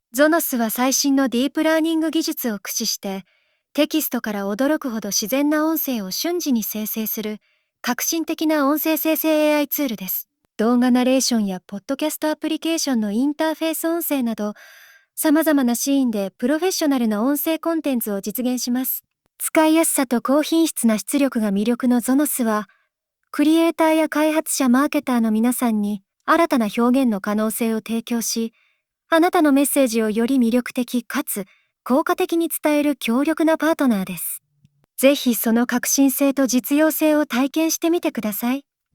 入力したテキストから、まるで実際に人が話しているかのような豊かな表現や感情が込められた音声を生成できます。
流暢な日本語で話してくれます。